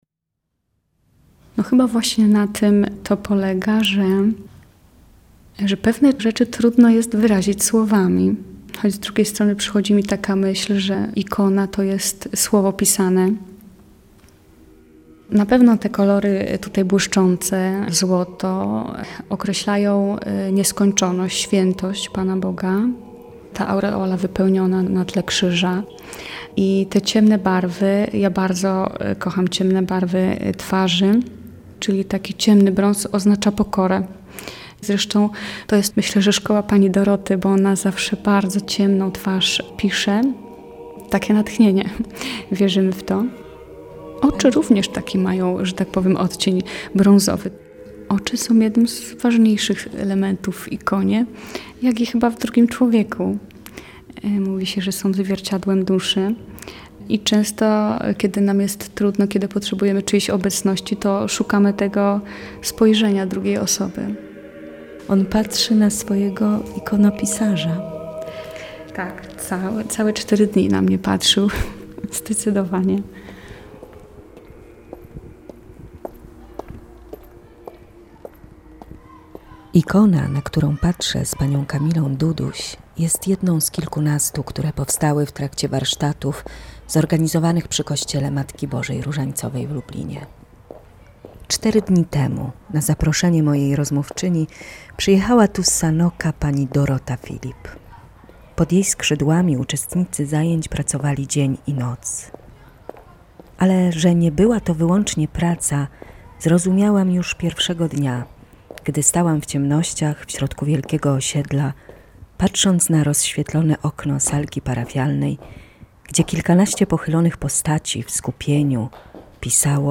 Reportaż